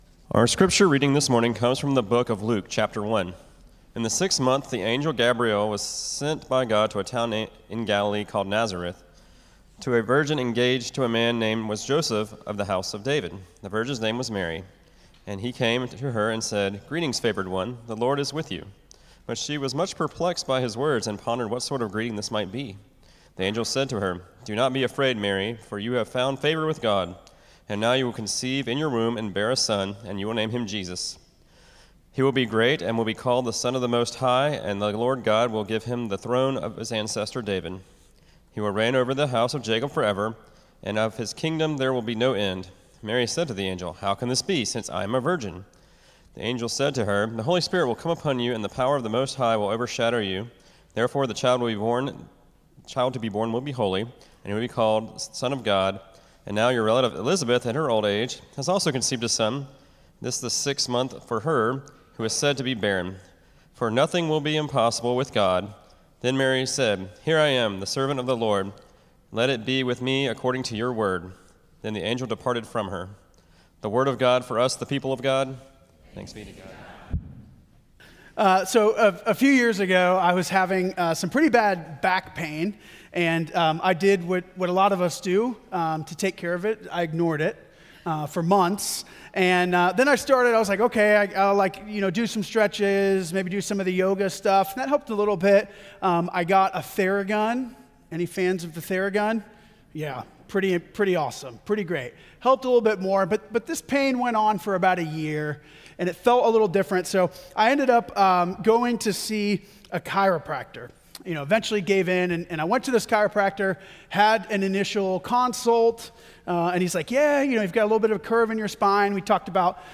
“Unhurried Advent” Sermon Series